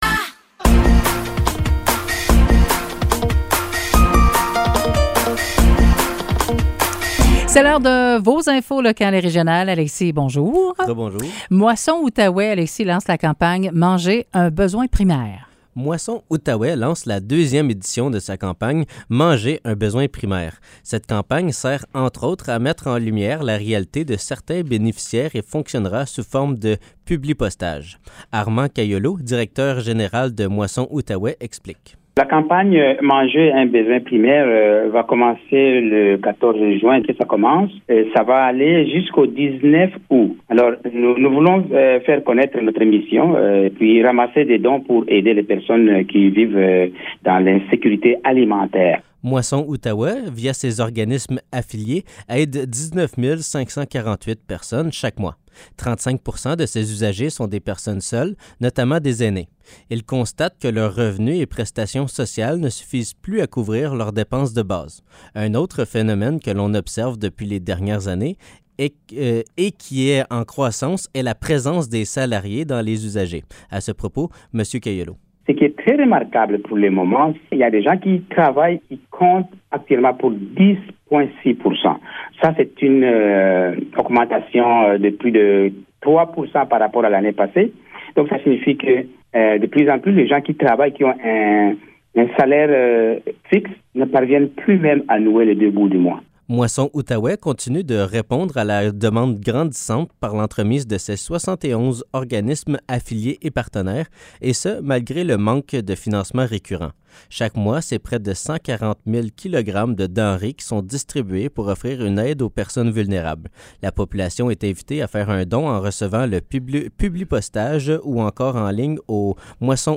Nouvelles locales - 14 juin 2023 - 10 h